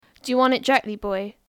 Examples of Cornish English
//dʒu wɒnt ɪʔ ˈdɻekli/bɔɪ//
Notice the retroflex "r" in directly.